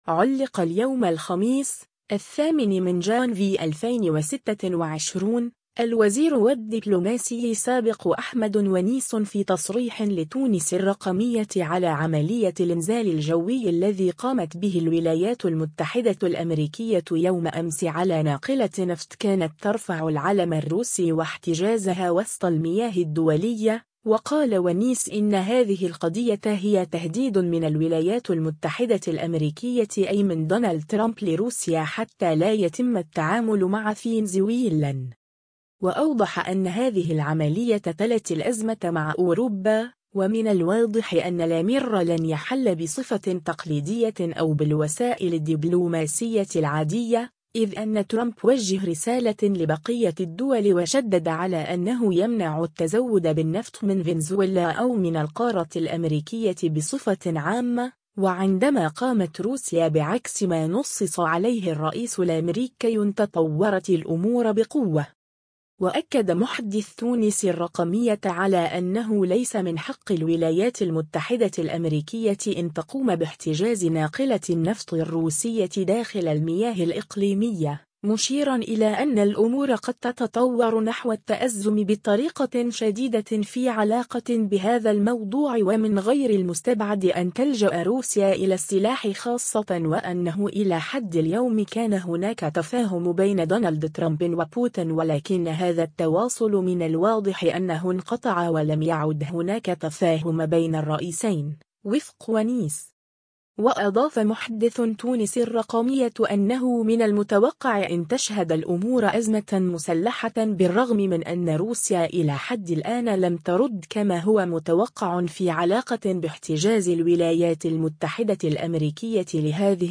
علّق اليوم الخميس، 08 جانفي 2026، الوزير و الدّبلوماسي السابق أحمد ونيس في تصريح لتونس الرّقمية على عملية الانزال الجوي الذّي قامت به الولايات المتحدة الامريكية يوم أمس على ناقلة نفط كانت ترفع العلم الروسي و احتجازها وسط المياه الدّولية، و قال ونيس إنّ هذه القضية هي تهديد من الولايات المتحدة الأمريكية أي من دونالد ترامب لروسيا حتى لا يتمّ التعامل مع فينزويلا.